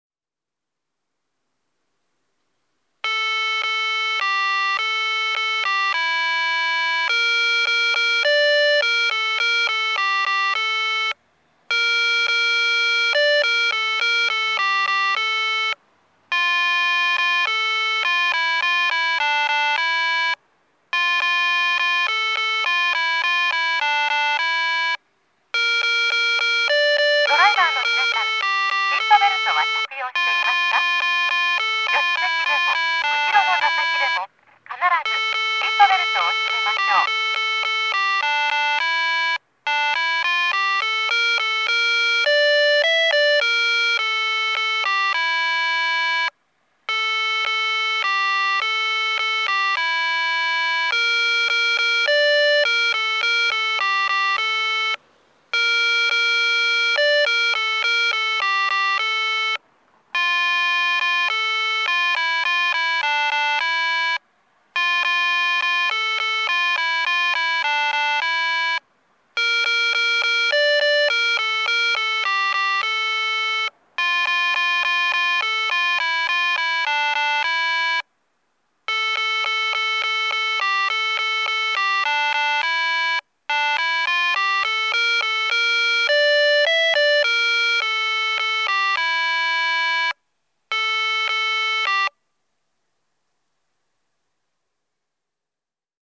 交通信号オンライン｜音響信号を録る旅｜大分県の音響信号｜[別府:0189]市営体育館先
市営体育館先(大分県別府市)の音響信号を紹介しています。